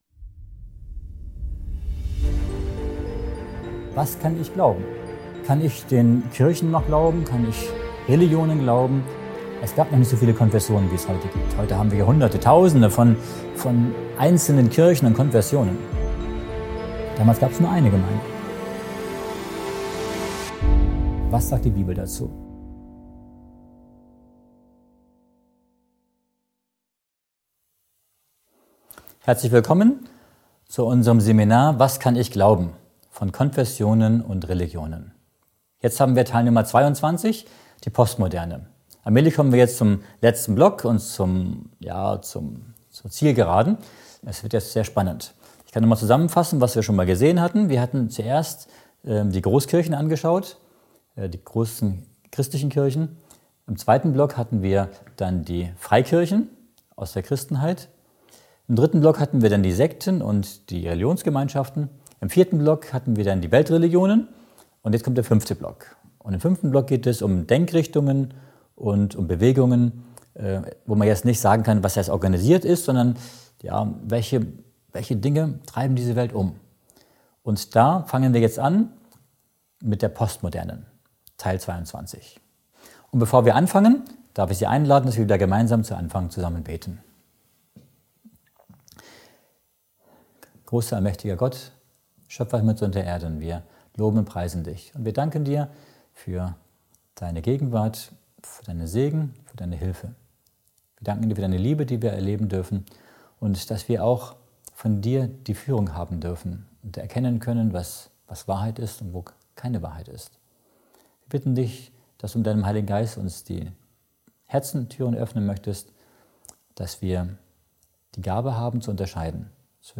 In diesem faszinierenden Vortrag wird die Entwicklung des Glaubens in der Postmoderne ergründet. Der unveränderte Wert der Bibel als Absolute steht dabei im Fokus.